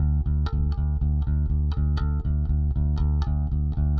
Live Bass Guitar Loops " Bass loop 120 bpm rock eights d
描述：小套的贝斯循环典型的摇滚八音，速度为120 bpm不同的音符（在文件名后面）。循环完美。有压缩器的线型低音信号。指点迷津。
Tag: 手指 摇滚 现场 吉他 巴萨吉他 120BPM 八分 低音 不断